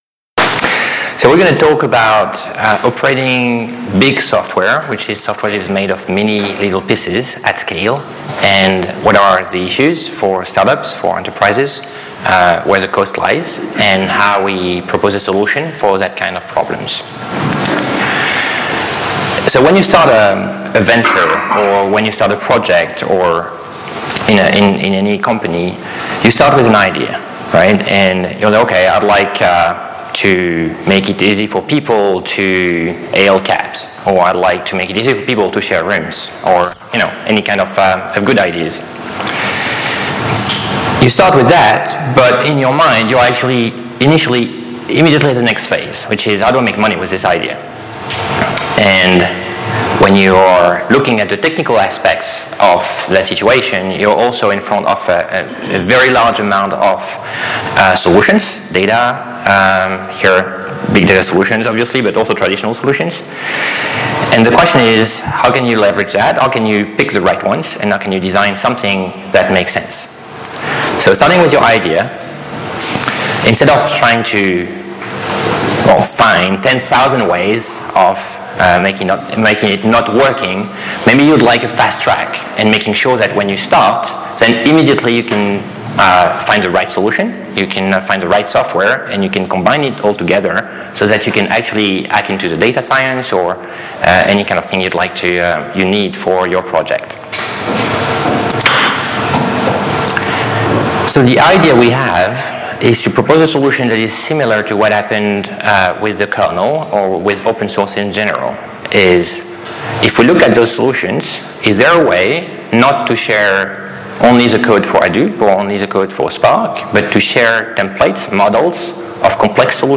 In this talk we explore how Juju can provide an Open Source method to model a multi-node Apache Spark cluster across a diverse set of substrates, and start adding other services to build additional solutions. This talk will include a demo, and users should be able to take all software shown to try for themselves in a free and Open Source manner.